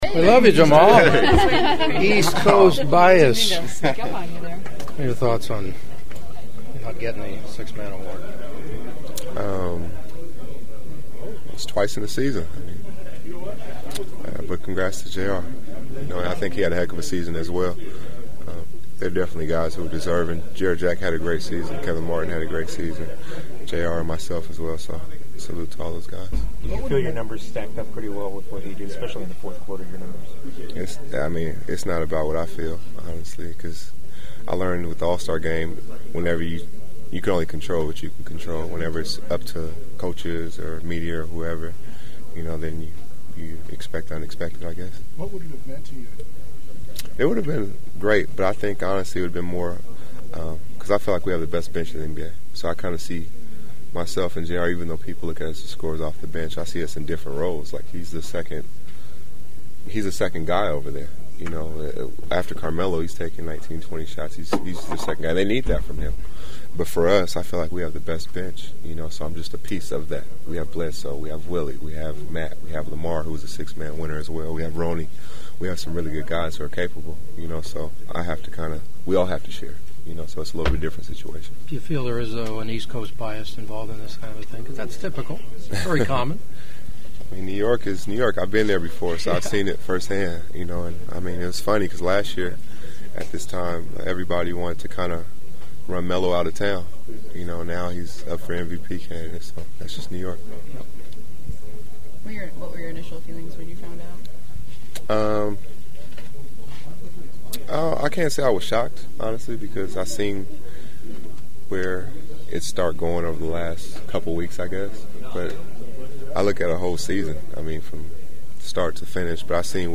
There’s a nice vibe inside Staples Center before tonight’s 2nd game of the opening round playoff series between the Clippers and Grizzlies with L.A. up 1 game to none…but it’s not as nice as it should be standing in front of the locker of Clips guard Jamaal Crawford who lost out to J.R. Smith of the Knicks for the NBA 6th man of the year award.